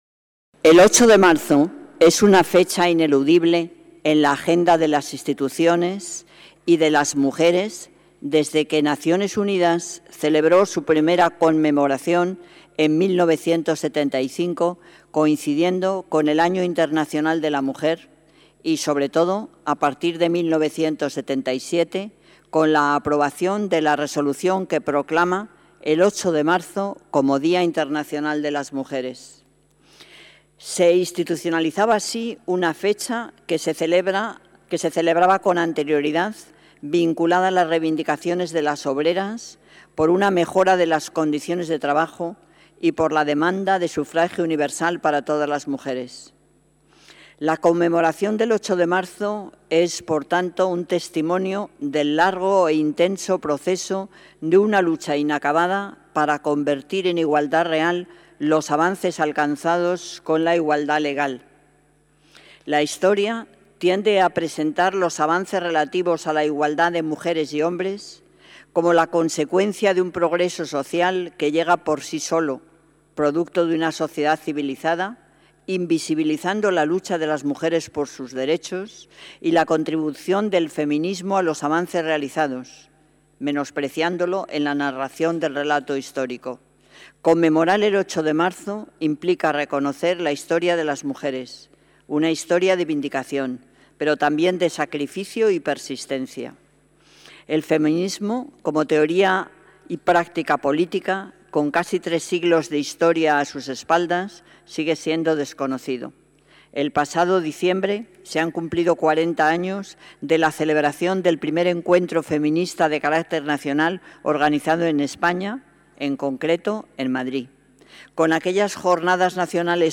Nueva ventana:Manuela Carmena, alcaldesa de Madrid